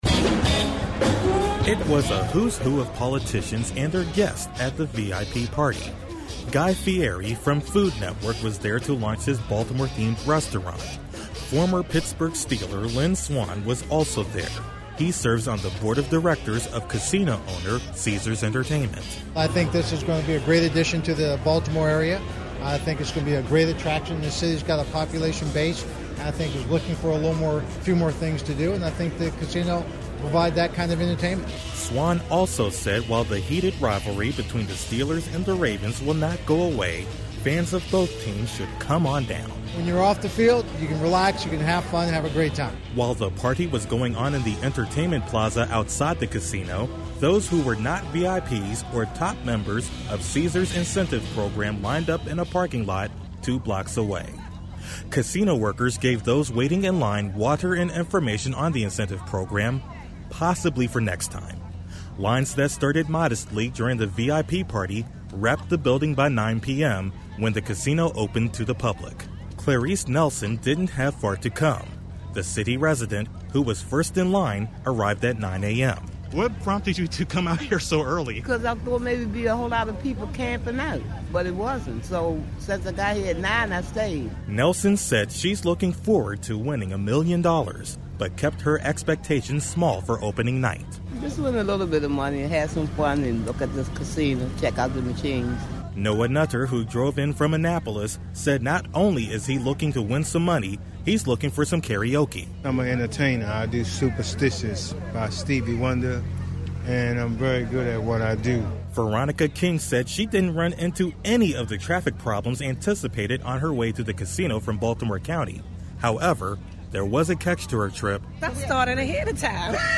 Tuesday’s grand opening of the Horseshoe Casino Baltimore had it all: sizzle, excited patrons and a protest.